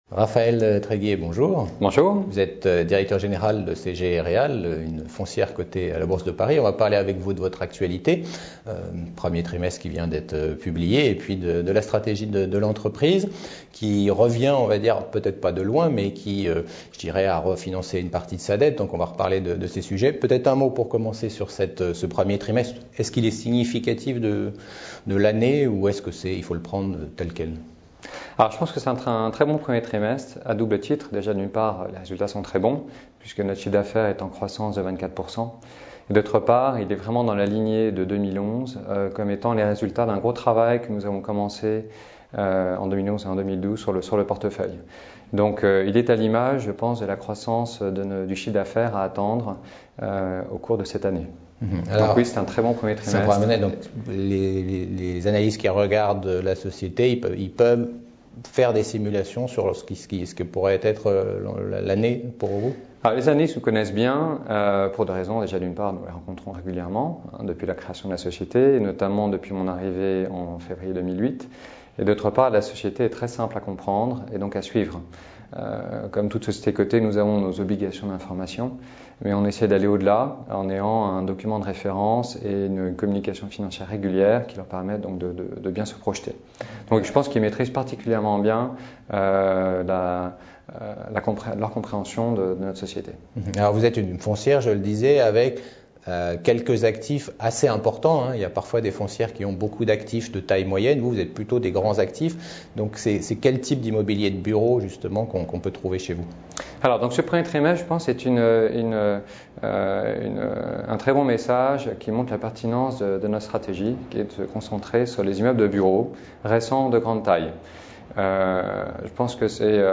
Actualités : Interview